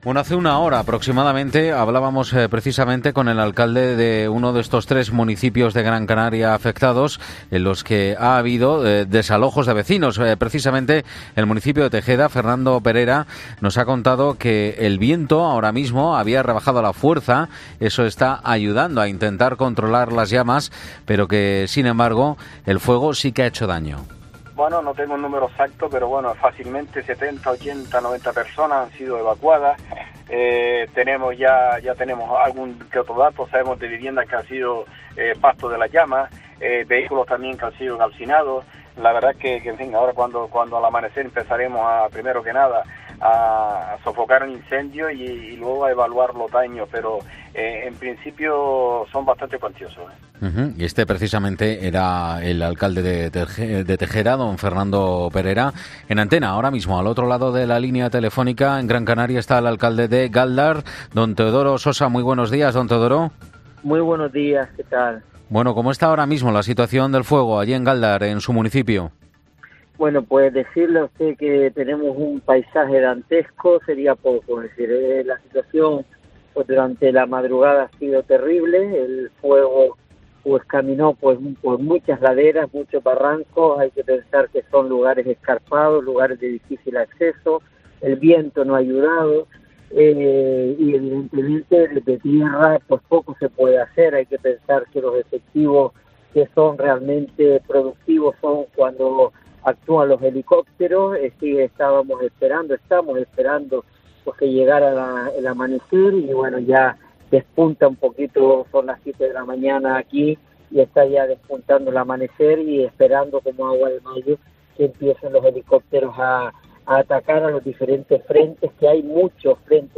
Este domingo en los micrófonos de COPE hablábamos con el alcalde de Gáldar, Teodoro Sosa, quien nos describía lo que estaba viendo "un paisaje dantesco" y quien confiaba que a lo largo del día pudiera ser controlado.